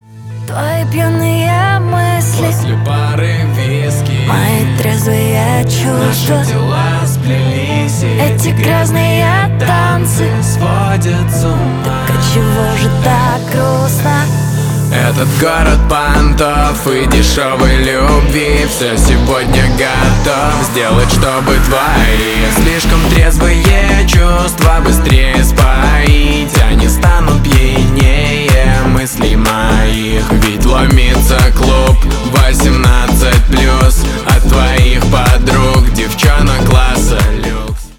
дуэт , клубные
поп
рэп